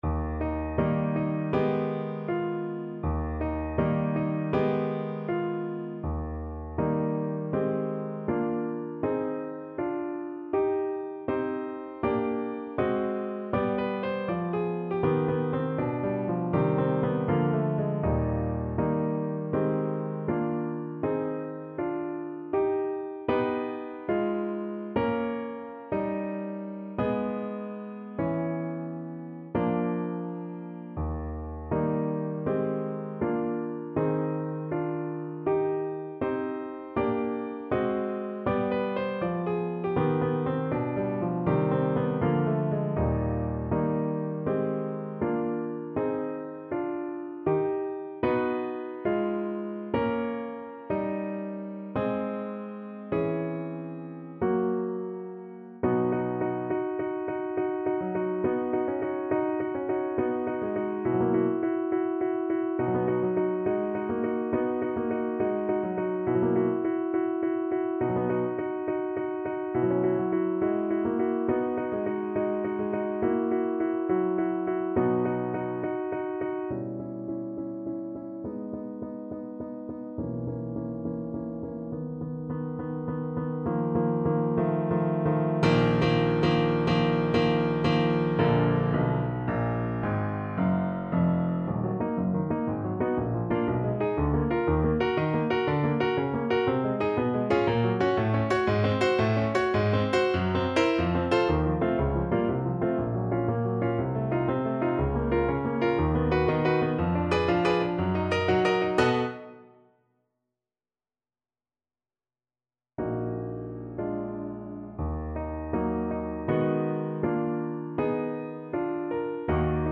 Moderato =80
Classical (View more Classical Trumpet Music)